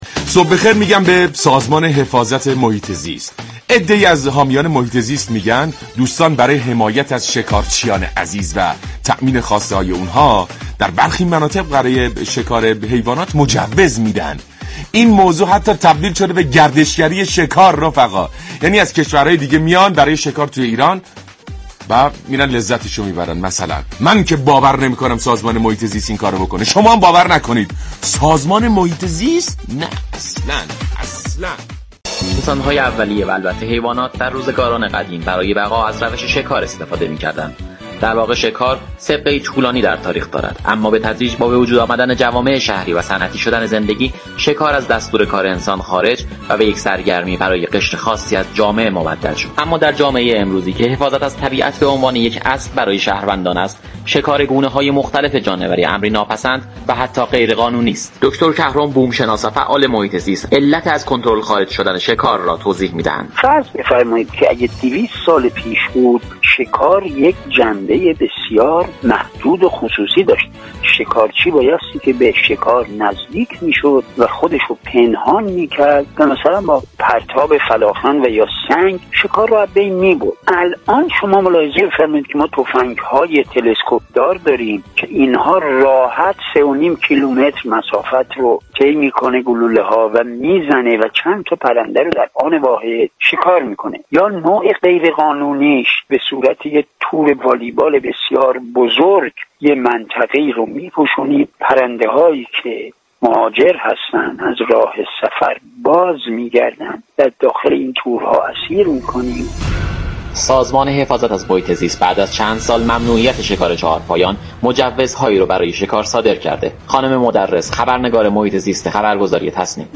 در این بخش از برنامه صبحگاهی رادیو ایران، اسماعیل کهرم، بوم‌شناس و فعال محیط زیست، شکاری که امروزه با سلاح‌های مجهز به دوربین انجام می‌شود را با شکار انسان پیش از اختراع اسلحه مقایسه کرد و با انتقاد از حمایت سازمان حفاظت محیط زیست از شکارچیان، نابودی چارپایان قابل شکار را طی 5 سال آینده هشدار داد.